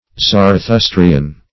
Search Result for " zarathustrian" : The Collaborative International Dictionary of English v.0.48: Zarathustrian \Zar`a*thus"tri*an\, Zarathustric \Zar`a*thus"tric\, a. Of or pertaining to Zarathustra, or Zoroaster; Zoroastrian.